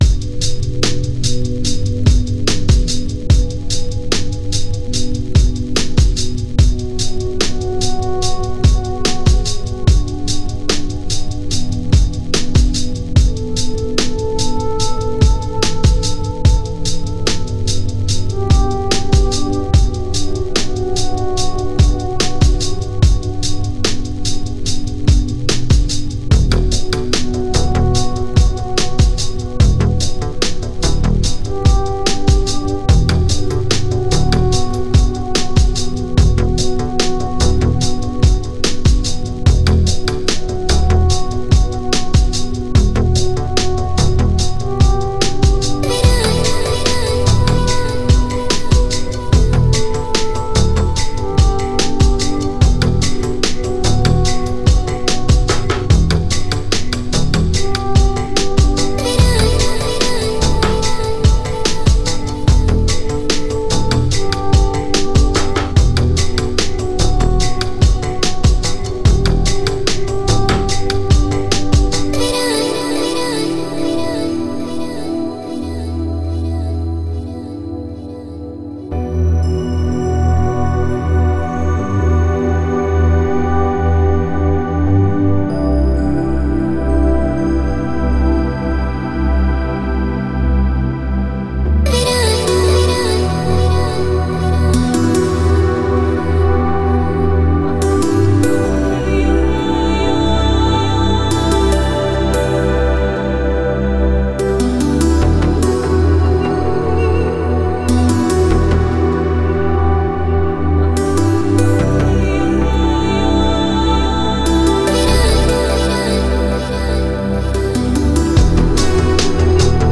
Balearic, Downtempo Издание